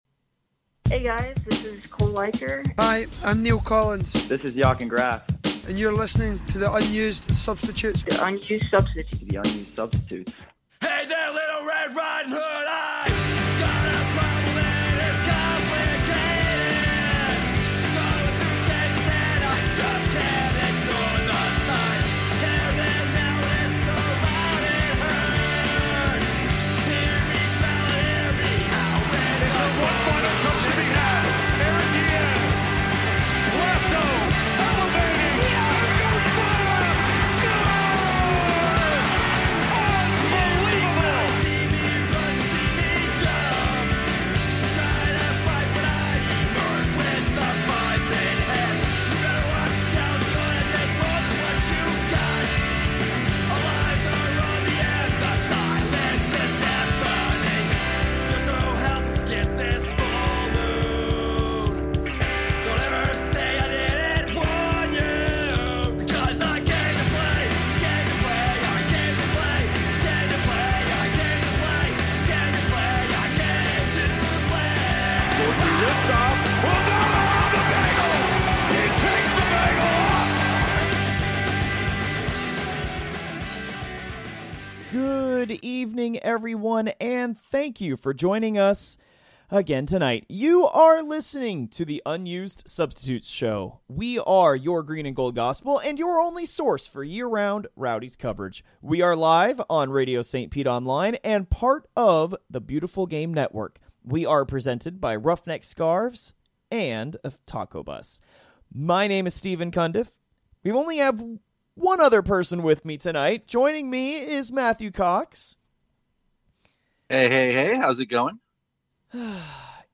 "Is it March Yet?" Rowdies Soccer Podcast with "The Unused Substitutes" 12-29-21 Airs live online most Wednesdays at 8pm ET on RadioStPete!